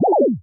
rhinodeath_01.ogg